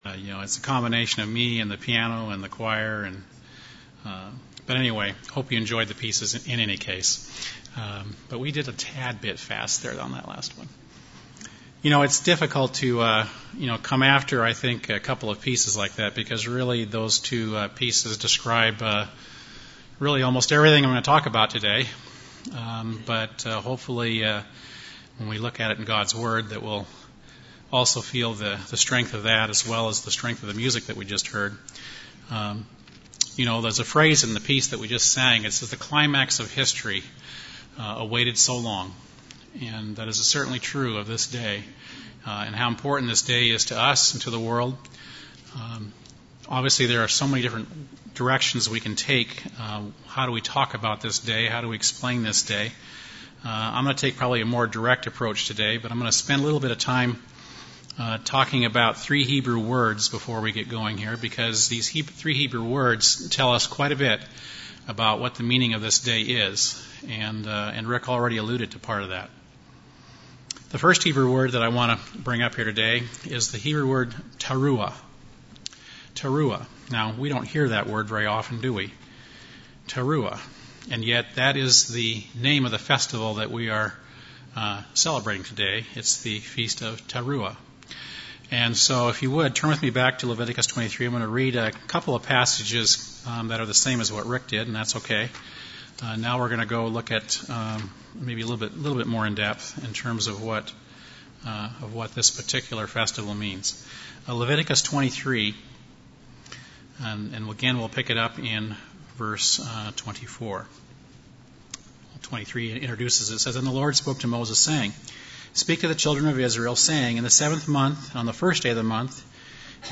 Sermons
Given in Burlington, WA